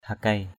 hakei.mp3